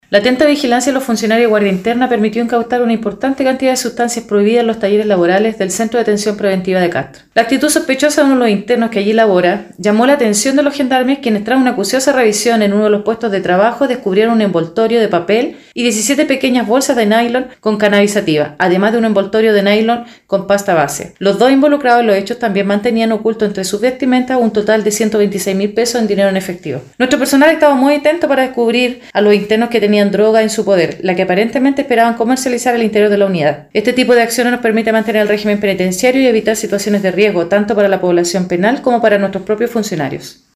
Respecto al decomiso se refirió la directora Regional de Gendarmería de la región de Los Lagos, Coronel Angélica Briones Castillo: